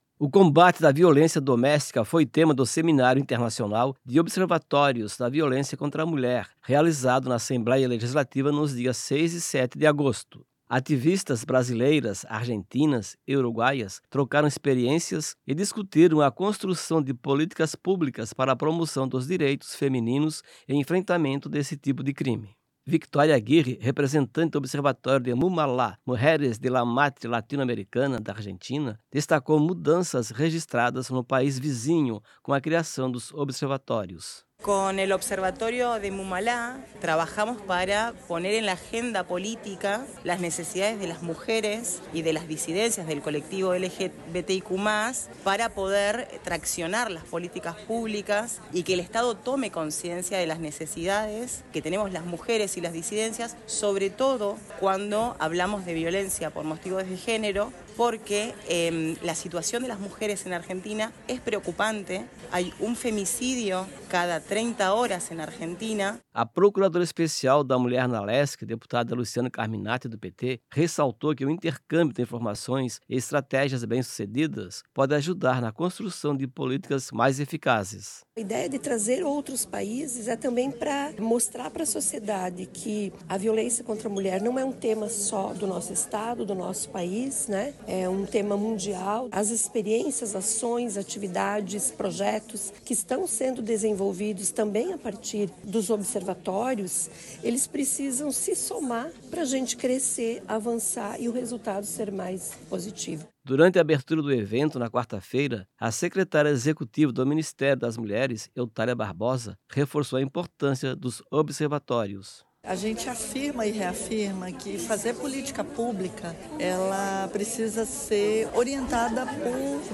Enrevistas com:
– deputada Luciane Carminatti (PT);
– Eutália Barbosa, secretária-executiva do Ministério das Mulheres;